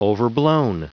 Prononciation du mot overblown en anglais (fichier audio)
Prononciation du mot : overblown